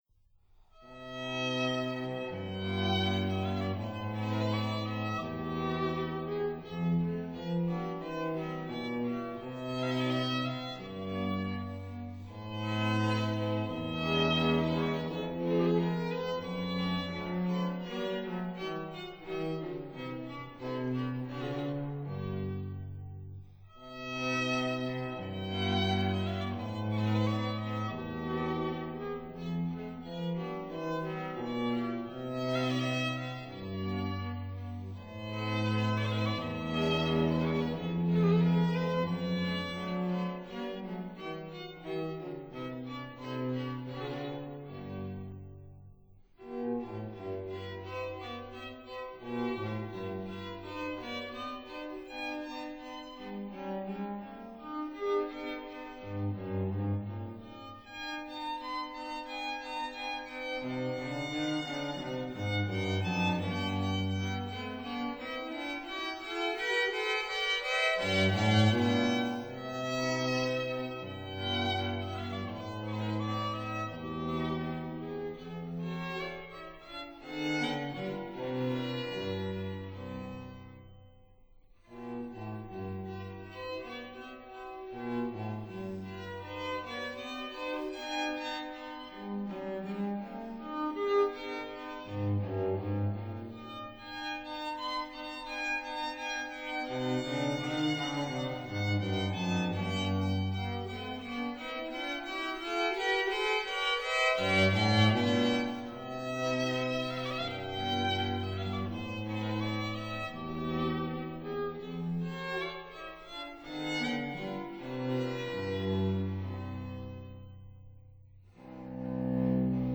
violin
viola
cello